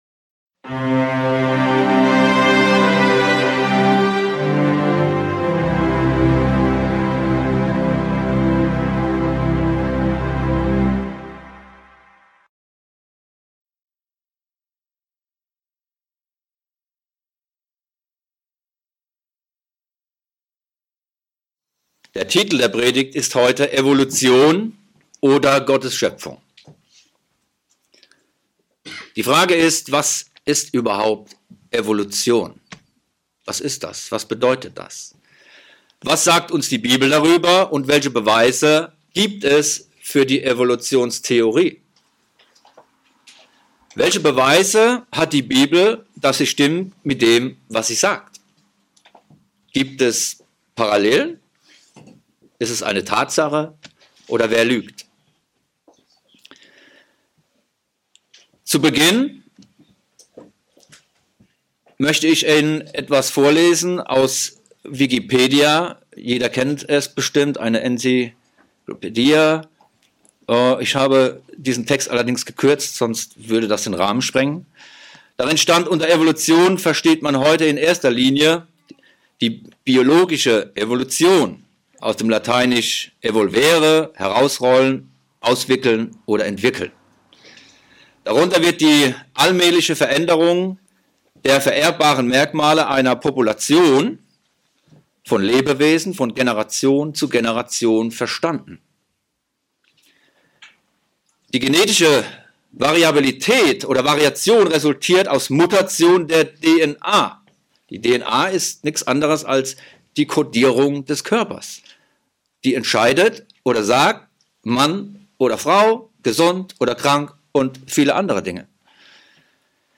Was sagt Gott über diese Evolutionstheorie? Schauen sie sich diese Predigt an und Sie werden sich wundern, was uns die Bibel darüber erzählt.